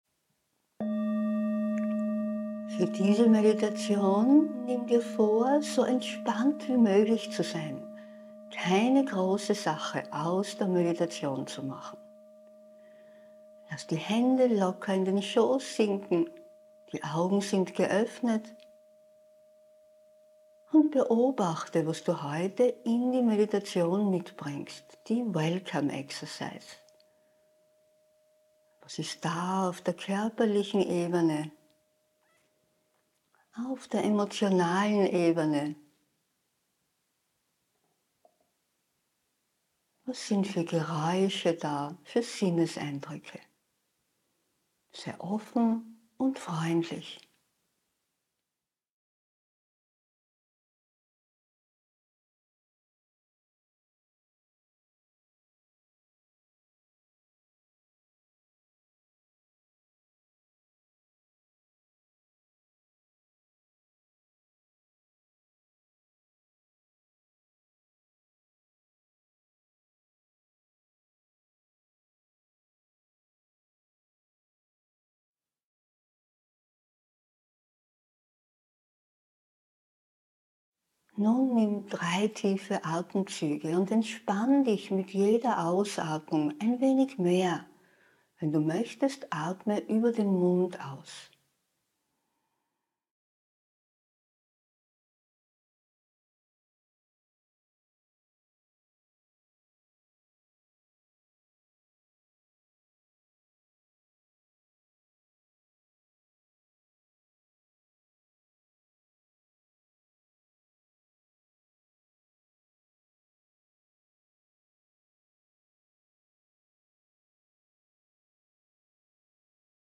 9-Minuten-Meditation-Entspannung.mp3